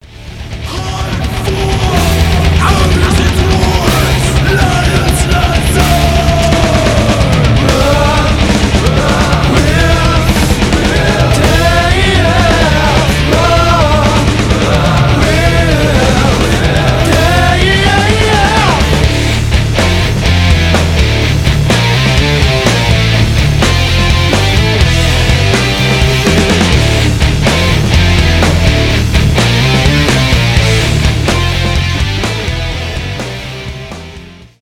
рок
progressive metal